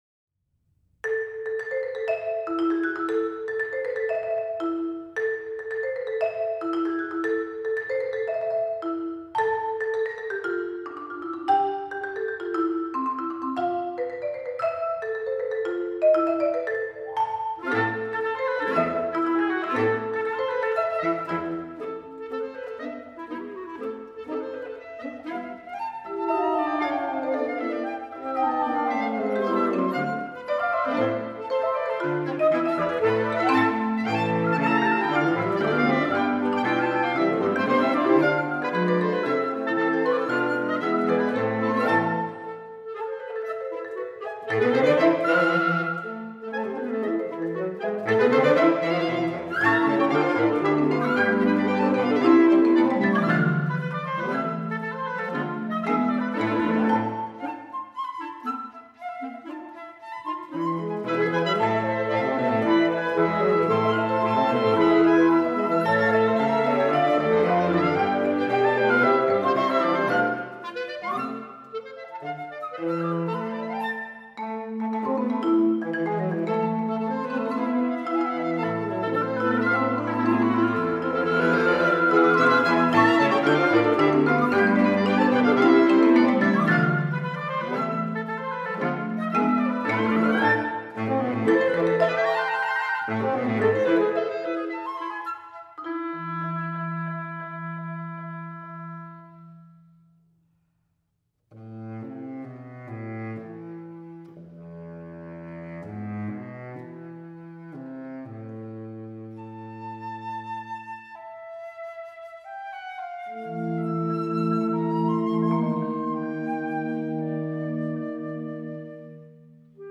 Partitions pour ensemble flexible.